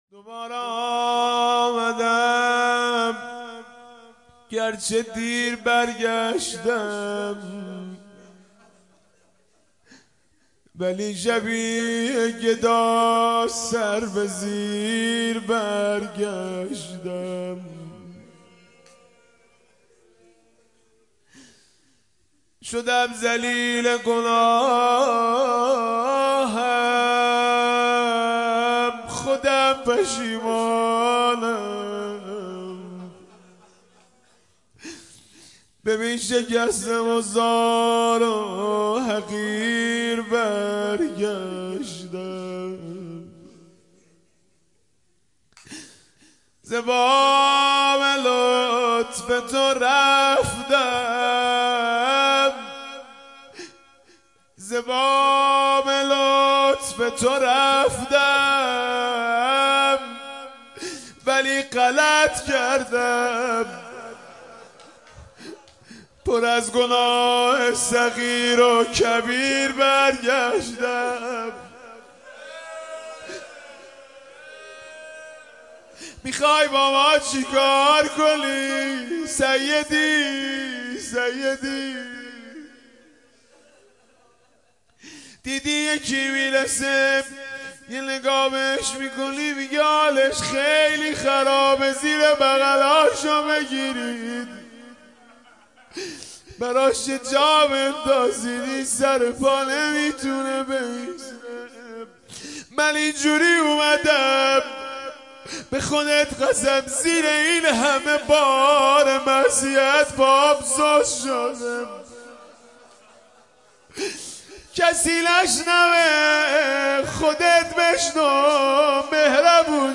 مداحی جدید حـــاج مهدی رسولی مراسم هفتگی پنجشنبه ۱۶ فـــــــــــــــــروردین ۱۳۹۷ هیأت ثارالله زنجان (رهروان امــام و شـهدا)